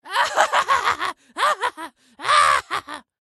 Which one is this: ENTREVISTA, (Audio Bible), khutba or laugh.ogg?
laugh.ogg